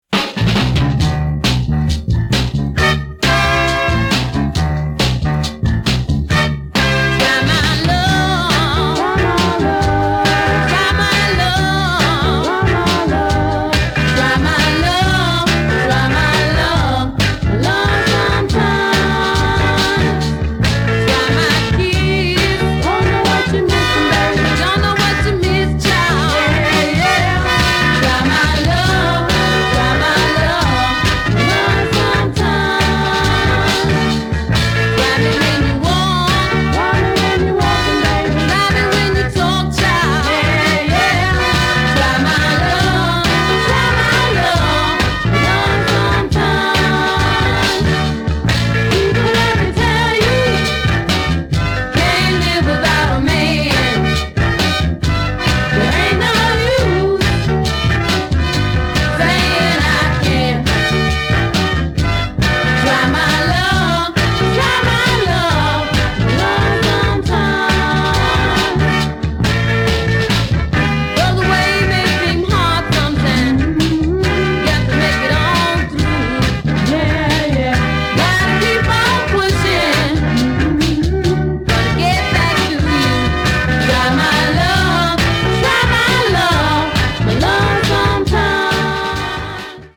MASTER RECORDINGS - R&B / SOUL